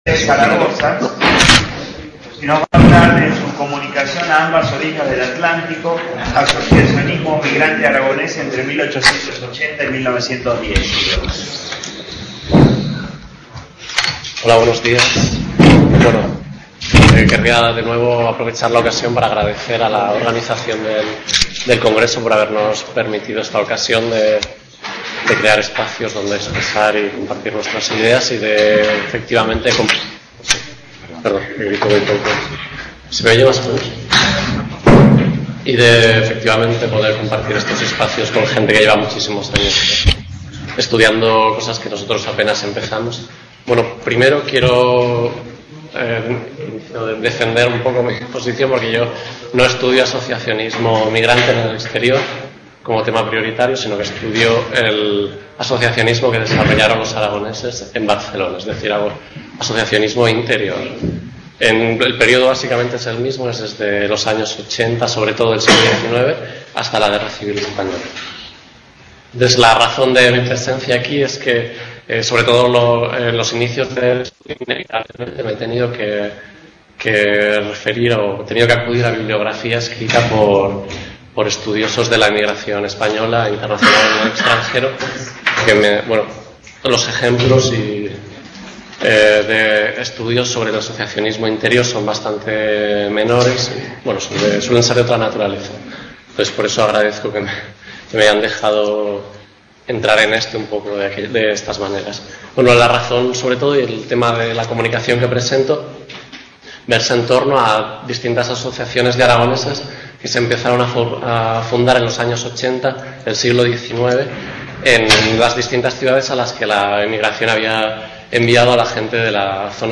| Tit: CONFERENCIAS | Autor:varios